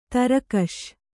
♪ tarakaṣ